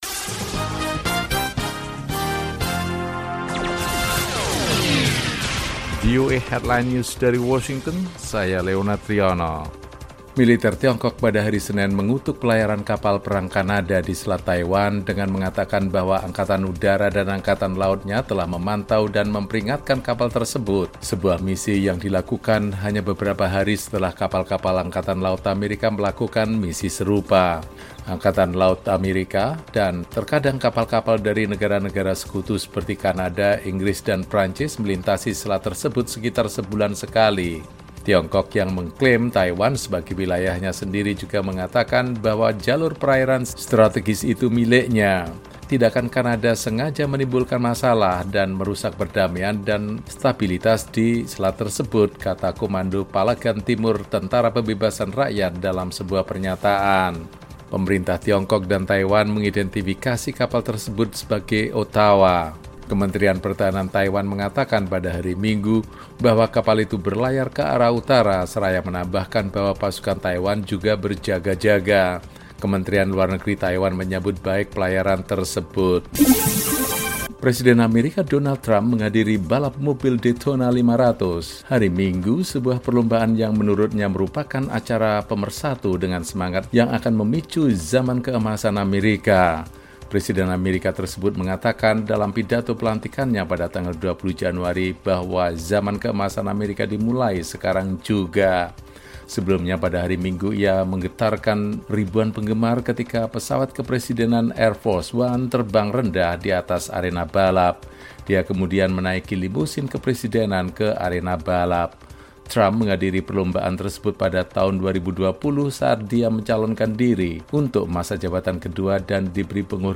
Laporan Radio VOA Indonesia